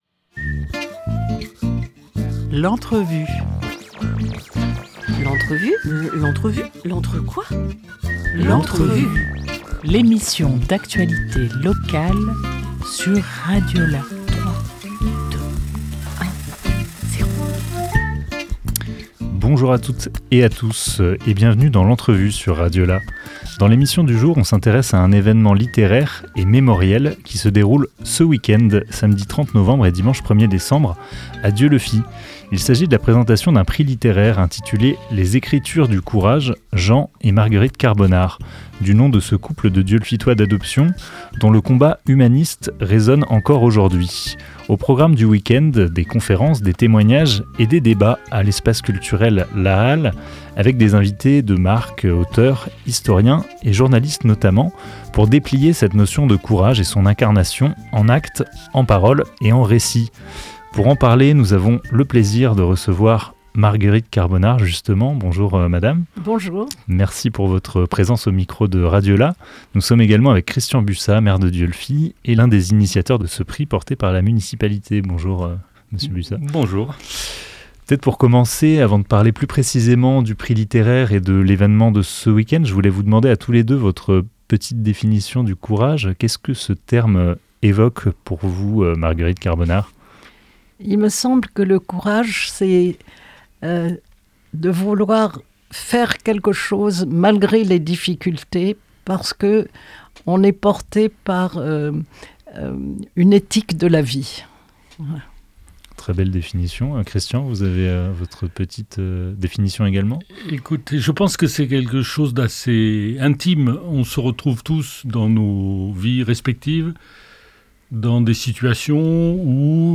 26 novembre 2024 13:43 | Interview